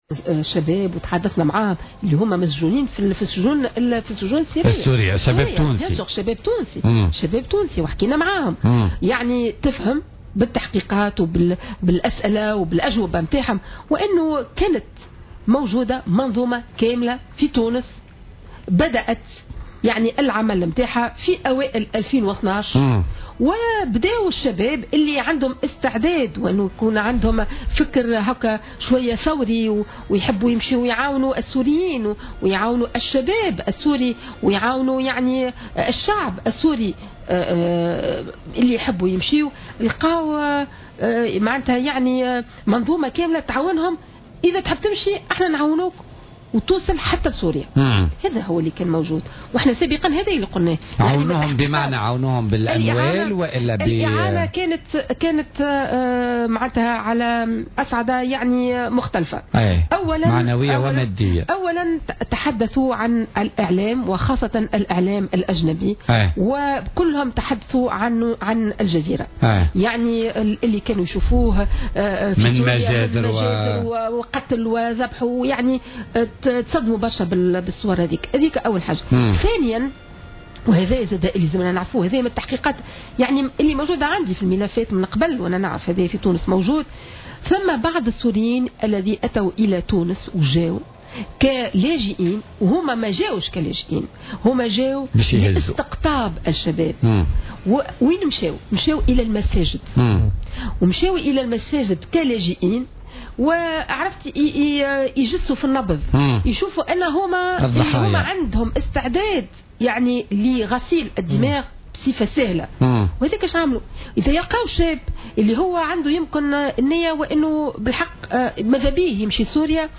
قالت النائب بمجلس نواب الشعب وعضو اللجنة البرلمانية للتحقيق في شبكات التسفير، ليلى الشتاوي، إن النواة الأولى لعمليات تسفير الشباب التونسي نحو بؤر التوتر وخاصة سوريا انطلقت فعليا منذ رمضان سنة 2012 نتاجا لانطلاق عمل "منظومة متكاملة" عملت على غسل أدمغتهم والتغرير بهم.